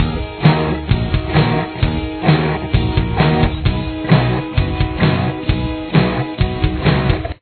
Verse
Guitar 2: